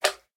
Sound / Minecraft / mob / magmacube / small1.ogg